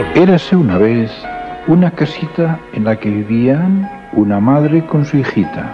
Versió radiofònica del conte "La caperutxeta vermella" de Charles Perrault amb motiu dels 300 anys de la seva publicació.